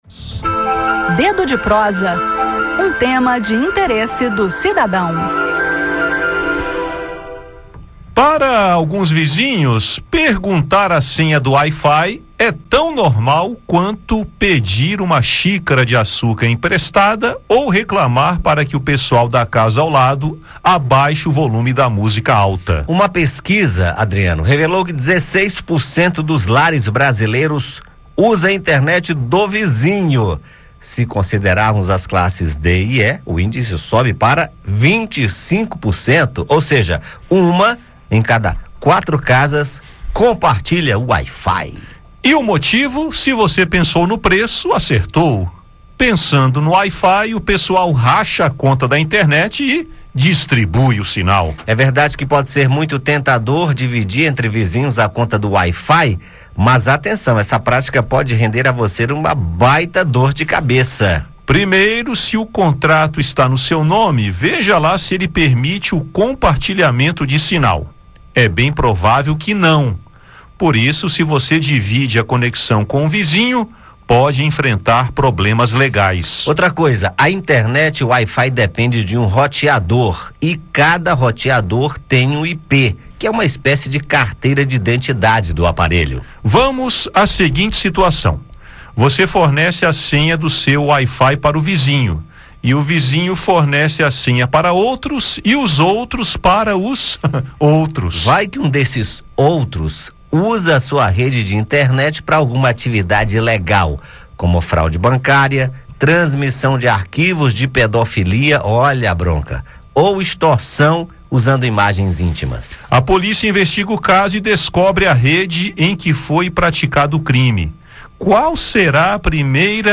No bate-papo desta quinta-feira (18) falaremos sobre uma prática considerada ilegal: o compartilhamento ou o furto do wi-fi.